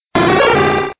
P3D-Legacy / P3D / Content / Sounds / Cries / 181.wav